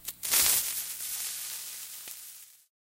fuse.ogg